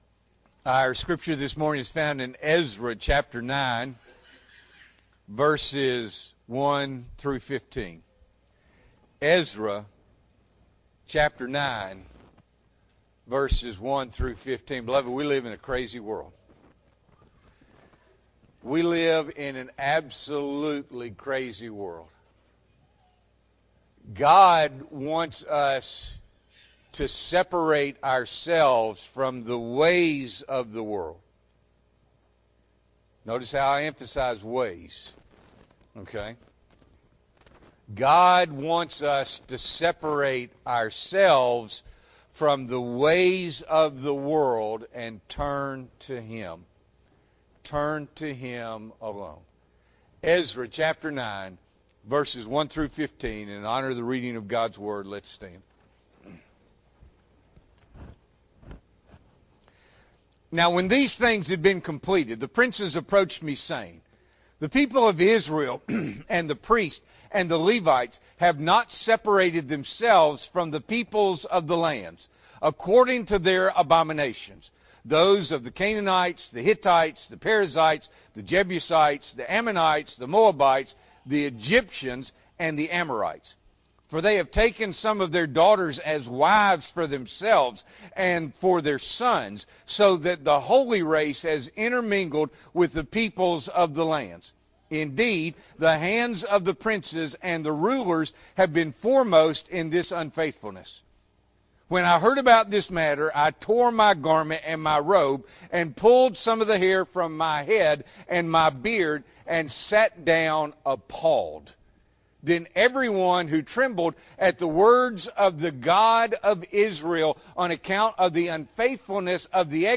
October 1, 2023 – Morning Worship